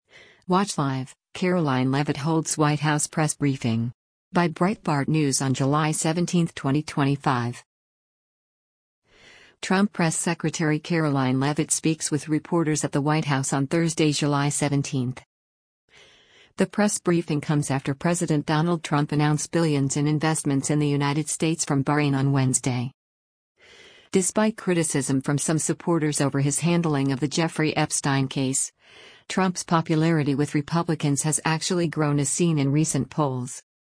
Trump Press Secretary Karoline Leavitt speaks with reporters at the White House on Thursday, July 17.